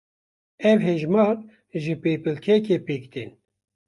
Pronounced as (IPA)
/hɛʒˈmɑːɾ/